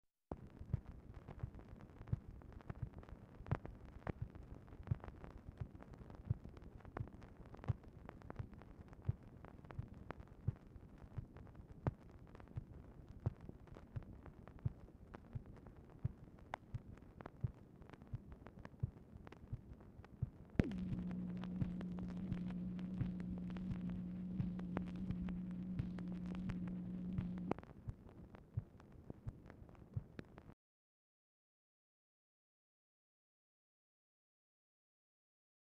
Telephone conversation # 9880, sound recording, MACHINE NOISE, 3/10/1966, time unknown | Discover LBJ
Format Dictation belt
Specific Item Type Telephone conversation